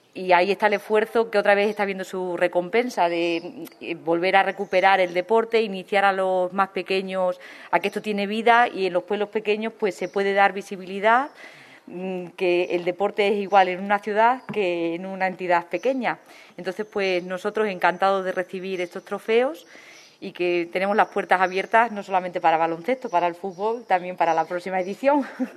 CORTES DE VOZ
Elisabeth Martín - Alcaldesa Garrovillas - Trofeos Deportes